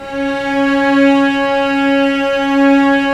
Index of /90_sSampleCDs/Roland LCDP13 String Sections/STR_Vcs II/STR_Vcs6 mf Amb